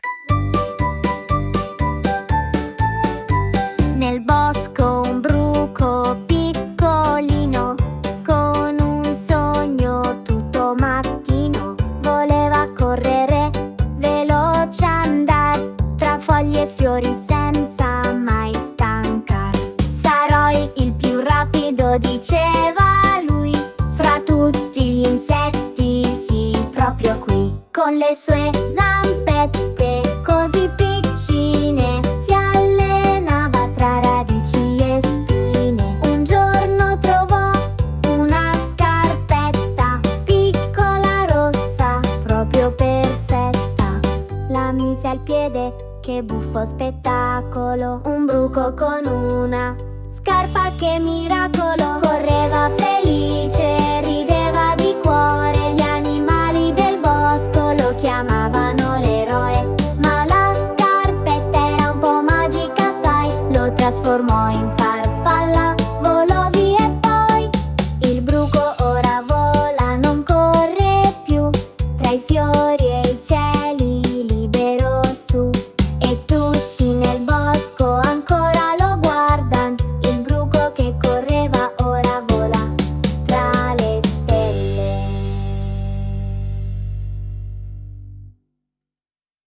🎧 Ascolta🎶 Filastrocche📺 GUARDA